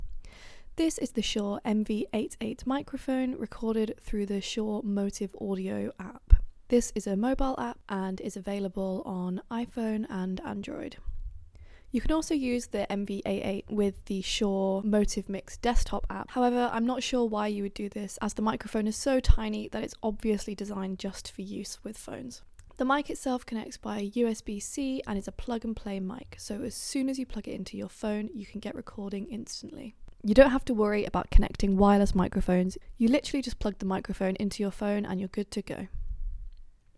• Gorgeous, warm vocal reproduction
My voice sounded rich and warm in every single clip.